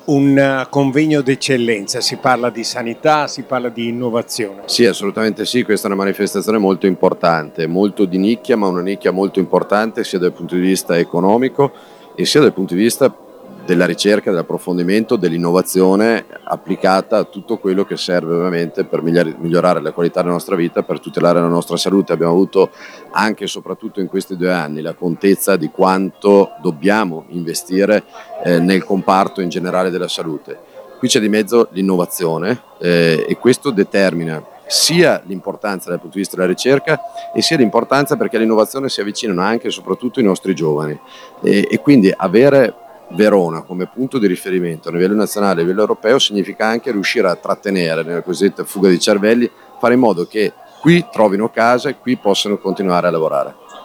Al nostro microfono anche:
Federico Sboarina, sindaco di Verona